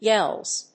/jɛlz(米国英語), jelz(英国英語)/